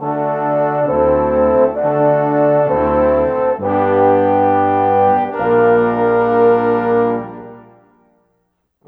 Rock-Pop 01 Brass 07.wav